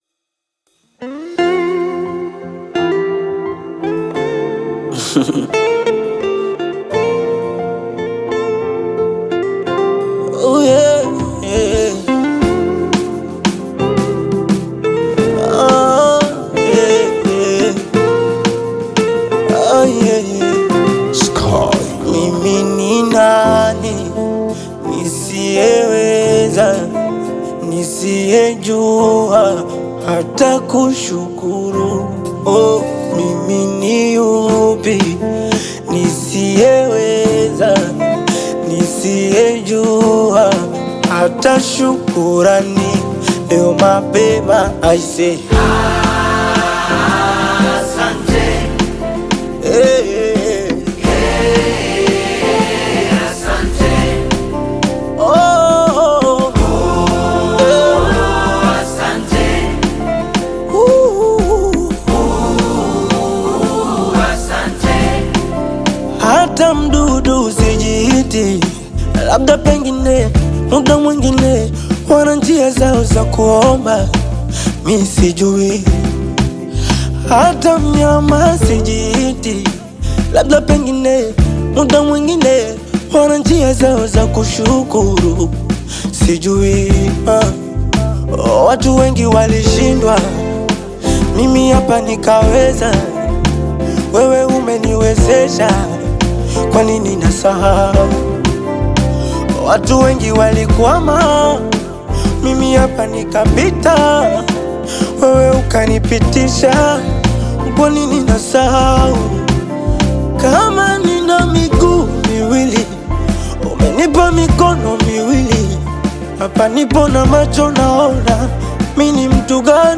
soulful vocals
a melody that exudes appreciation